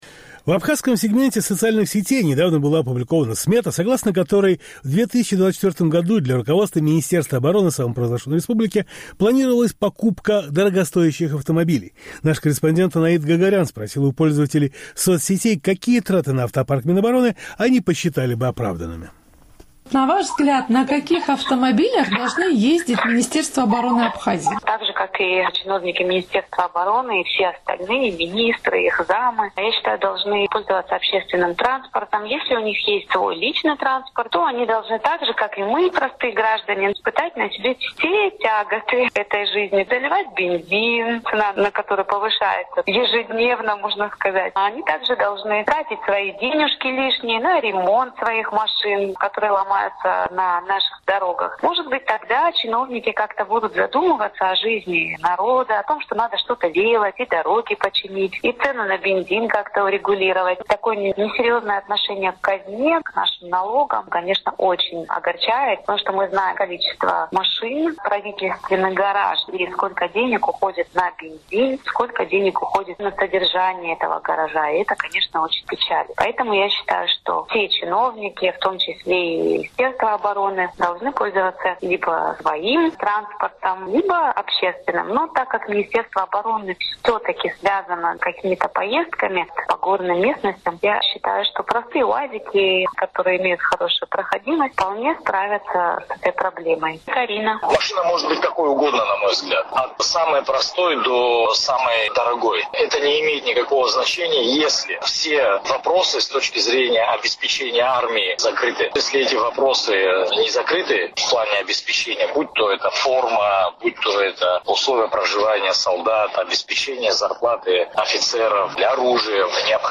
Сухумский опрос – об автотранспорте абхазского Минобороны